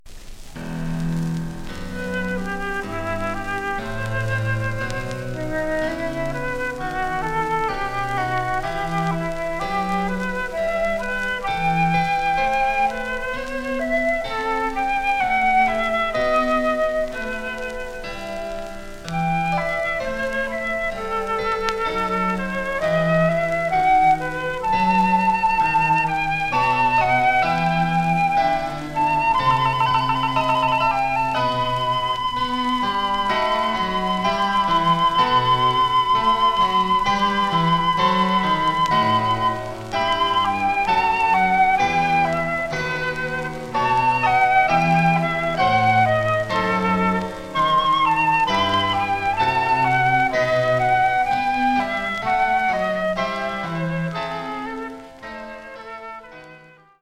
(1948年4月12日パリ録音)